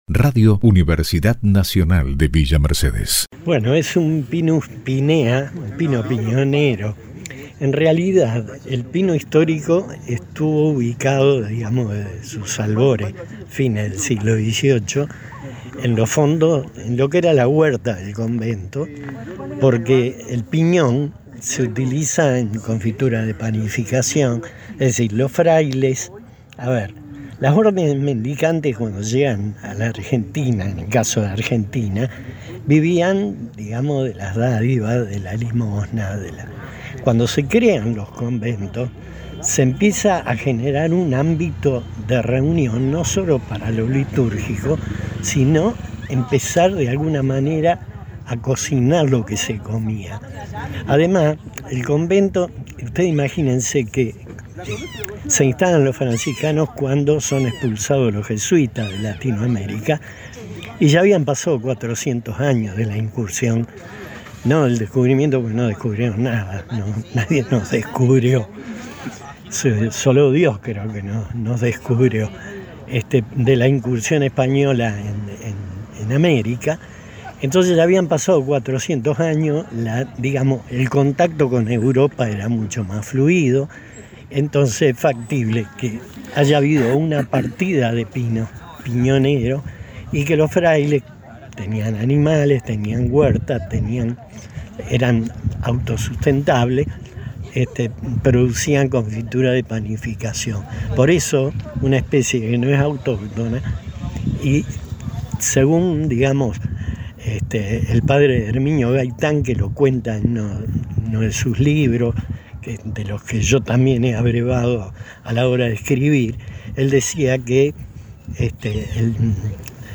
Al término del acto, Prensa Institucional recabó la opinión del Rector Marcelo Sosa